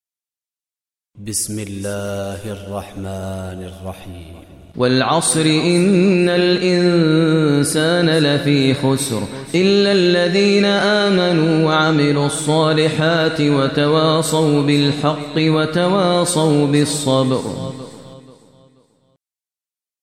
Surah Asr Recitation by Sheikh Maher Mueaqly
Surah Asr, listen online mp3 tilawat / recitation in Arabic recited by Imam e Kaaba Sheikh Maher al Mueaqly.